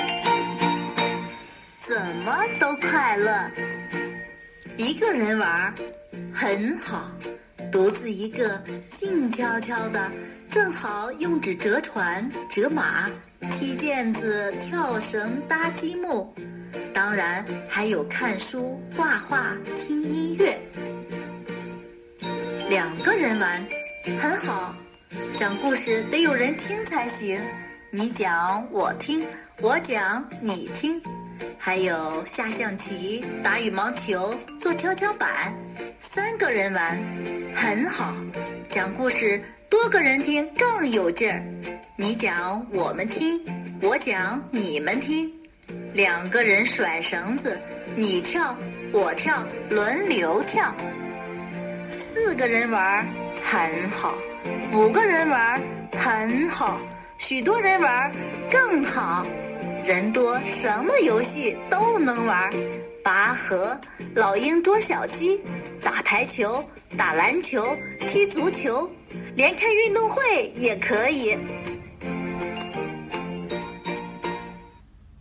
7.怎么都快乐 课文朗读.mp3